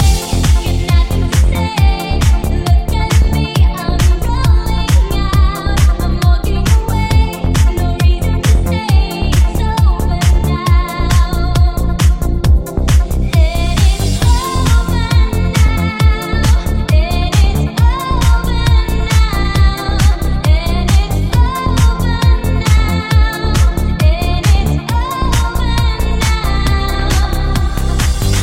light deep house releases
Genere: deep, slap, tropical, ethno, remix